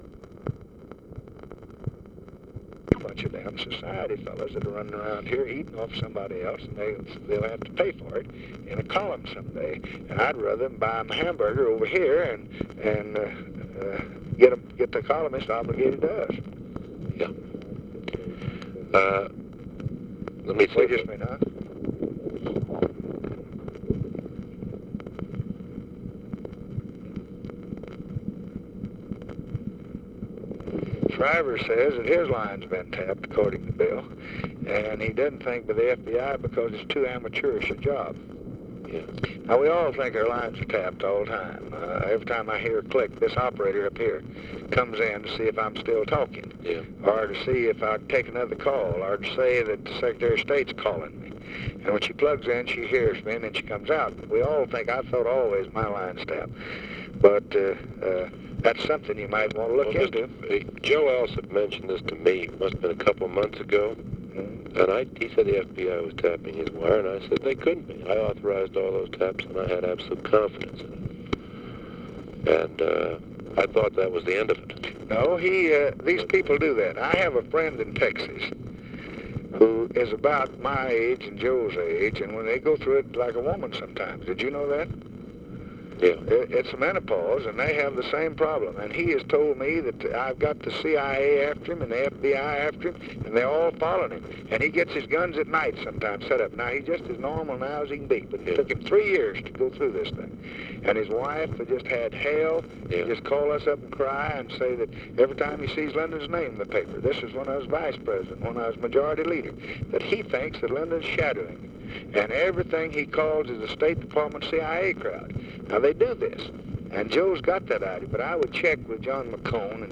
Conversation with NICHOLAS KATZENBACH and BILL MOYERS, March 29, 1965
Secret White House Tapes